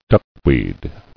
[duck·weed]